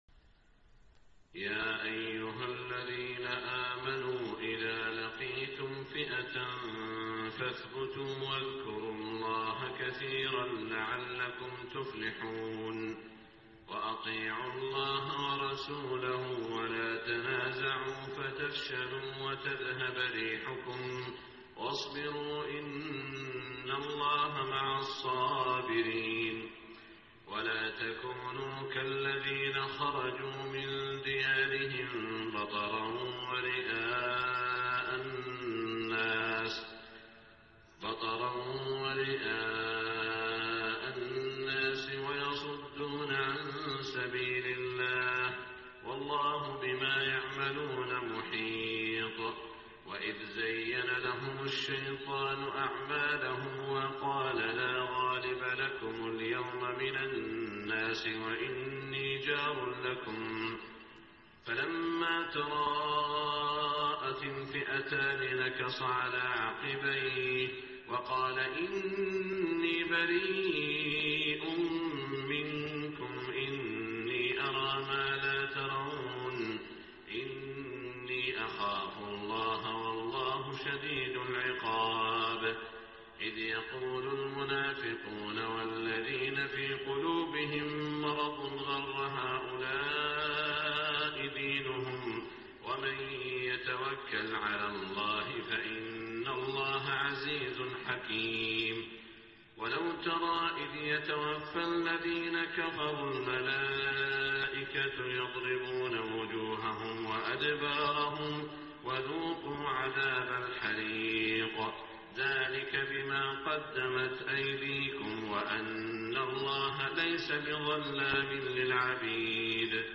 صلاة الفجر 4-3-1426 من سورة الأنفال > 1426 🕋 > الفروض - تلاوات الحرمين